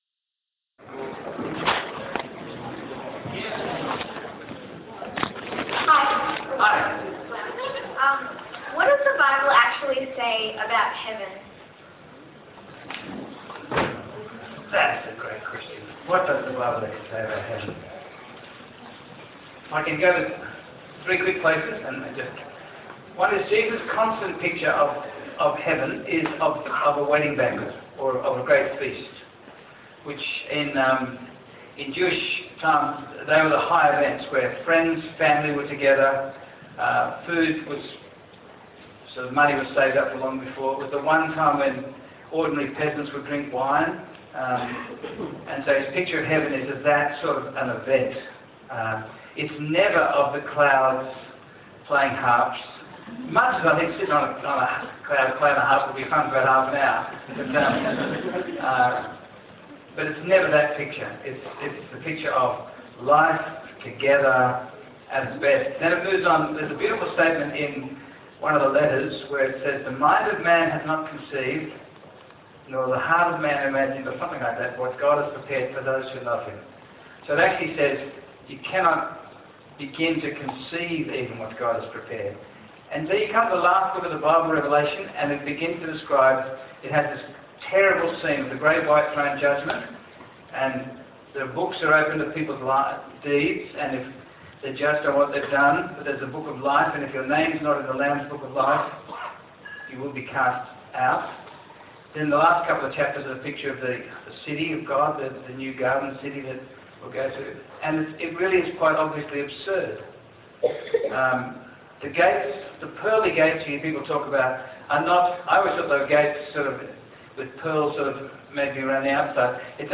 Questioners
question time